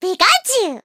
adds gen 7 icons & cries